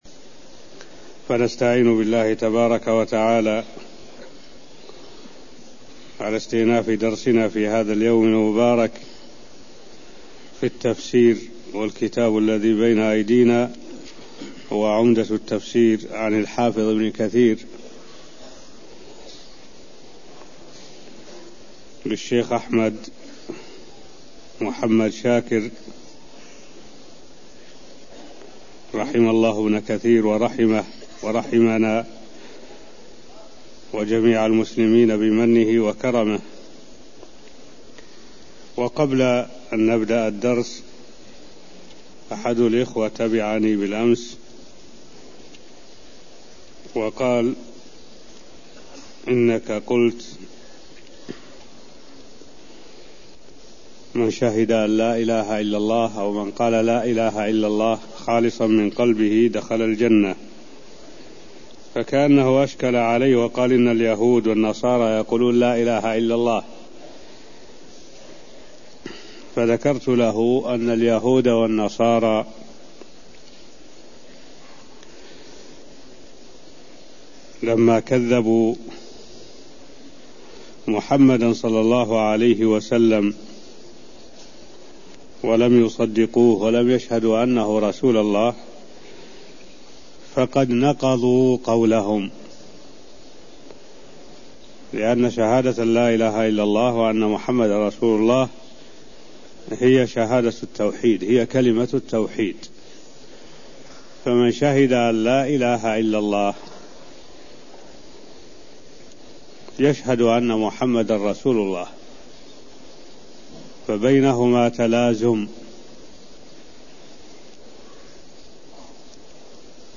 المكان: المسجد النبوي الشيخ: معالي الشيخ الدكتور صالح بن عبد الله العبود معالي الشيخ الدكتور صالح بن عبد الله العبود تفسير الآيات 40ـ41 من سورة البقرة (0030)